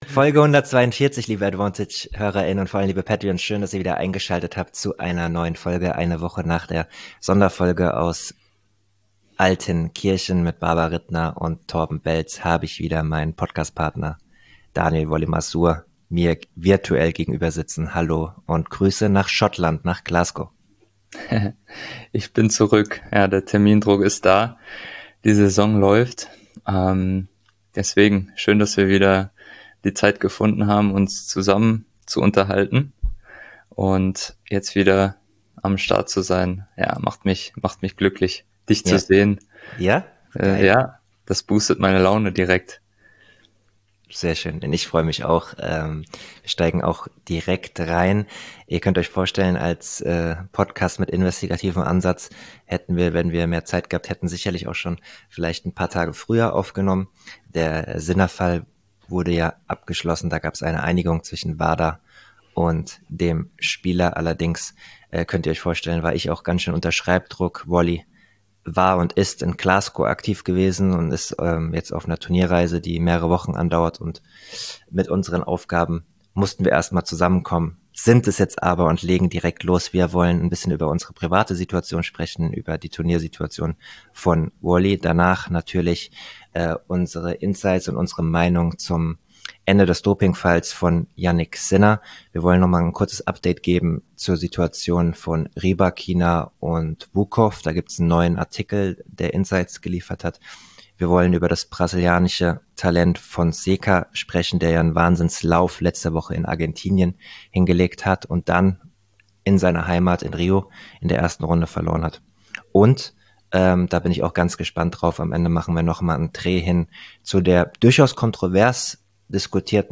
Der Davis-Cup-Kapitän im Interview